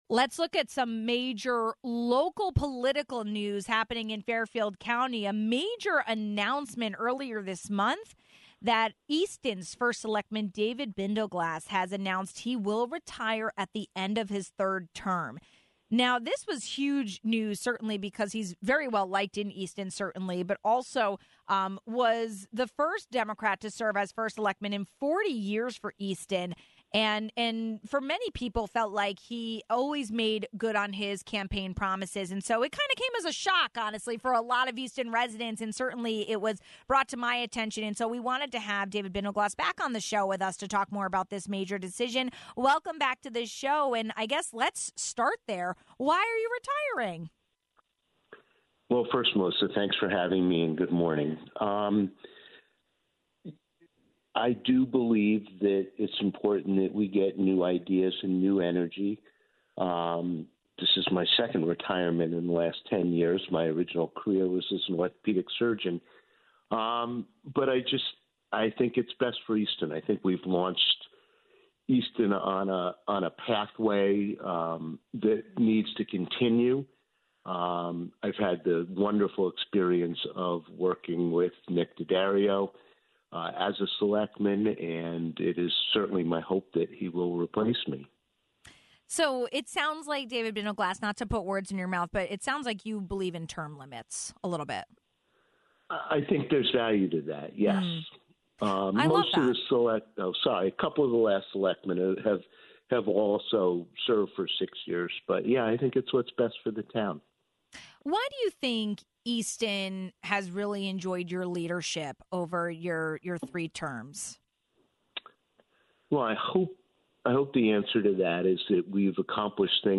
After completing his third term, Easton First Selectman David Bindelglass announced he is retiring. We asked him about his decision and his biggest takeaways in office.